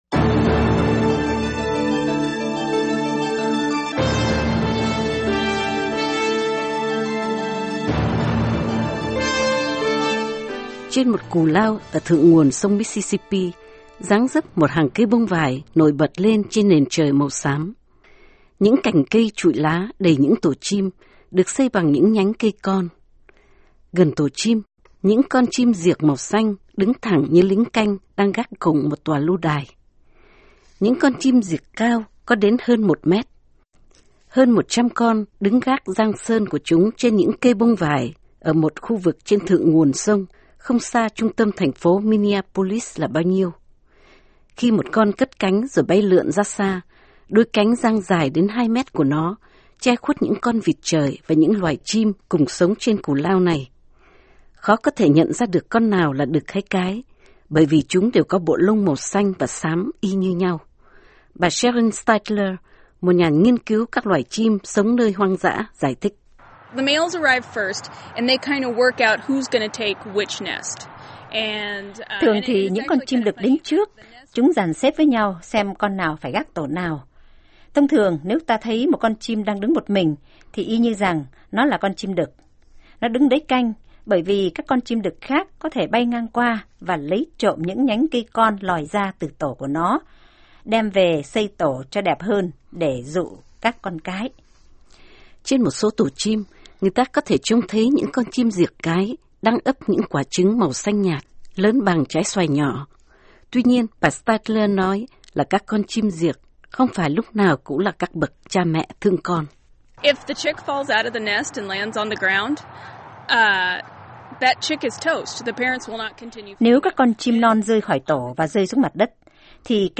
science_blue_herons_06may10_voa.Mp3